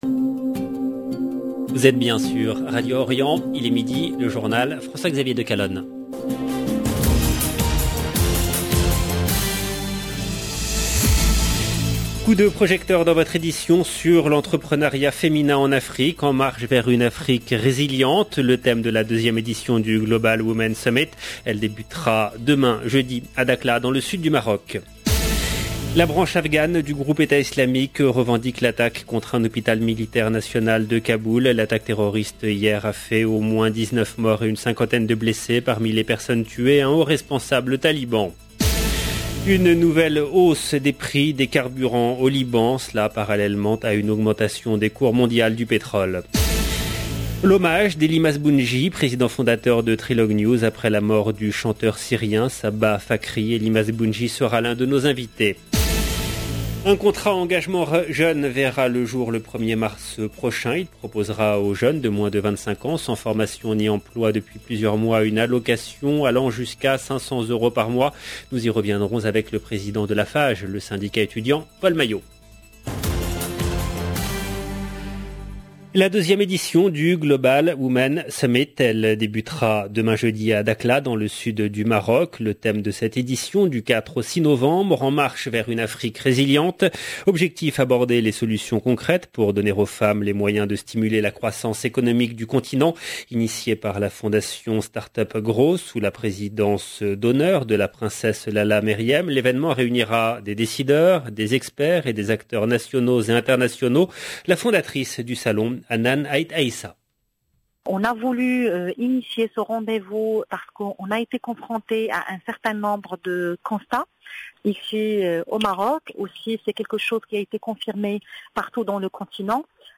EDITION DU JOURNAL DE 12 H EN LANGUE FRANCAISE DU 3/11/2021